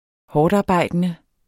Udtale [ -ɑˌbɑjˀdənə ]